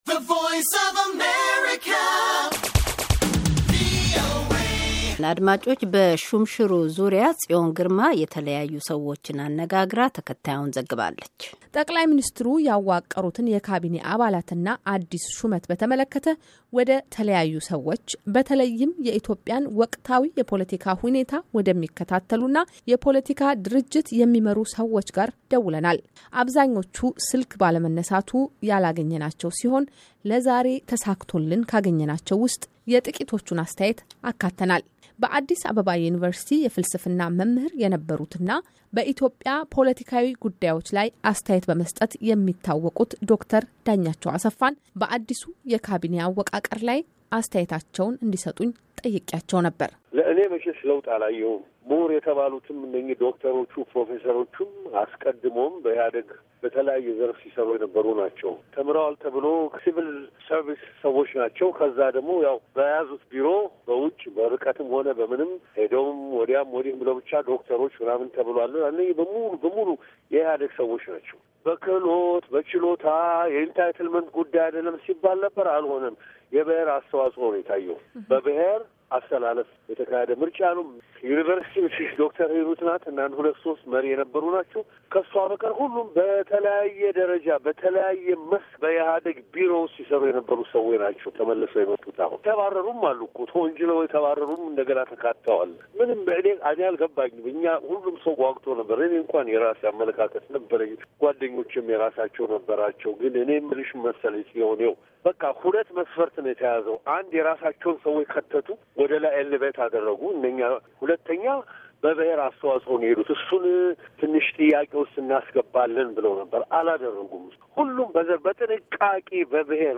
by የአሜሪካ ድምፅ
በአዲሱ ካቢኔ አወቃቀር ላይ አስተያየታቸውን እንዲሰጡን የኢትዮጵያን ወቅታዊ የፖለቲካ ሁኔታ ወደሚከታተሉና የፖለቲካ ድርጅት የሚመሩ ሰዎች ጋር ደውለናል። አብዛኞቹ ስልክ ባለመነሳቱ ሳናገኛቸው ቀርተናል። በነገው ምሽት በዚህ ጉዳይ ላይ ተጨማሪ ዘገባ ይዘን እንመለሳለን።